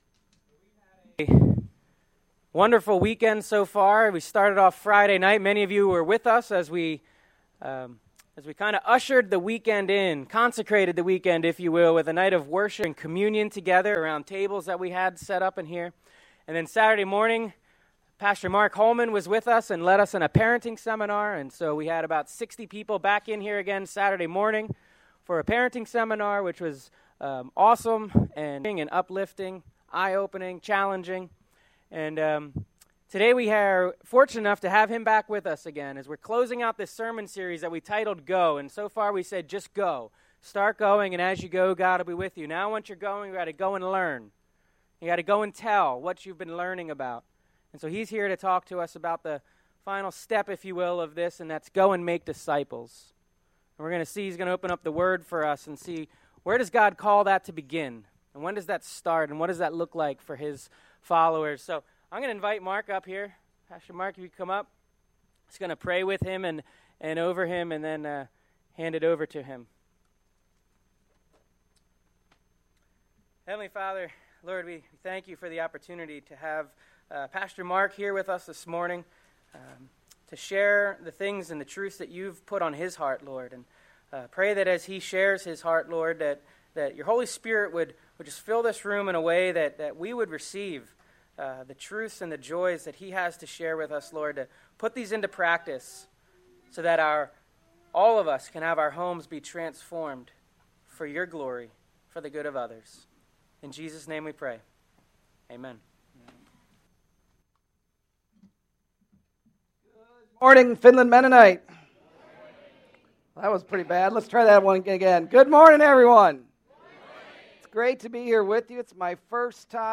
Sermon Series - Aug 07 - Go!